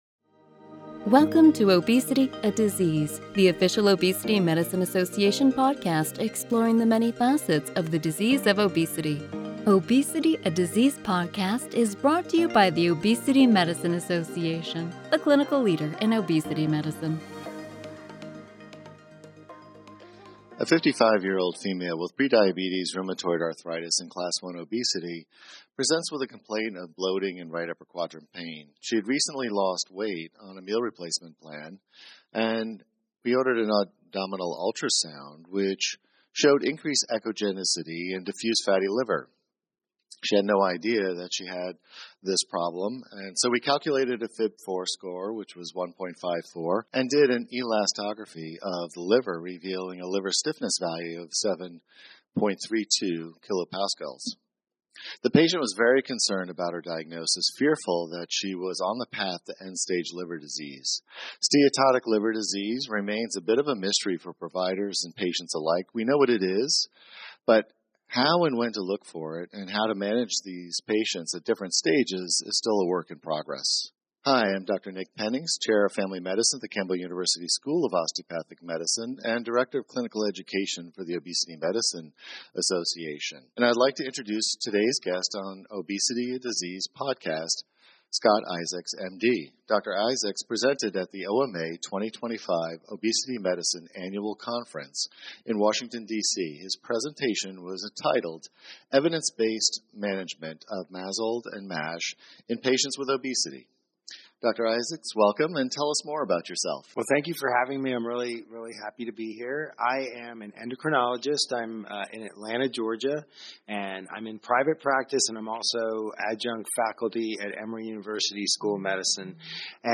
Episode 115: Clinical Conversations: Evidence Based Management of MASLD and MASH in Patients with Obesity